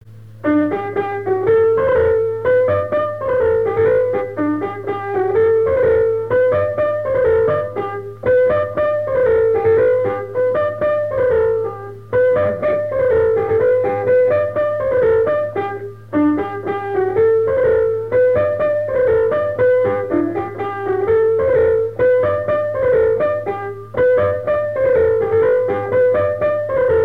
danse : branle : avant-deux
Trois avant-deux au piano
Pièce musicale inédite